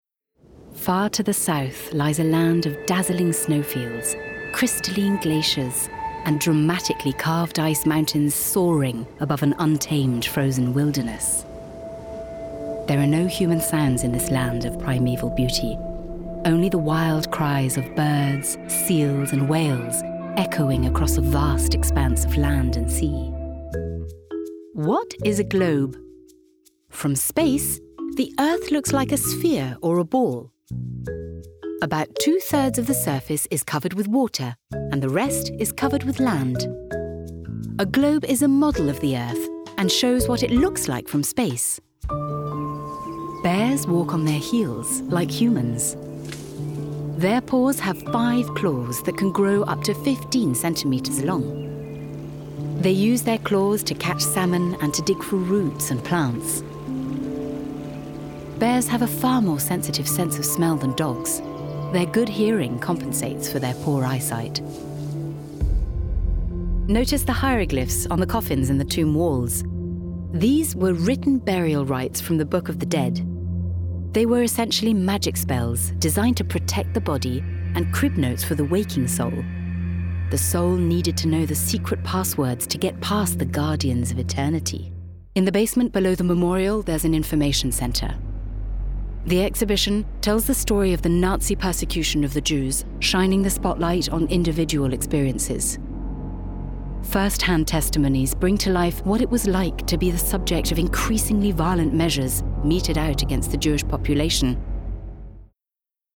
Inglés (Británico)
Cálida, Llamativo, Versátil, Seguro, Natural
Explicador